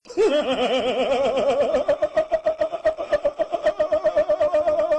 Play, download and share laughkeke original sound button!!!!
laughkekekeke.mp3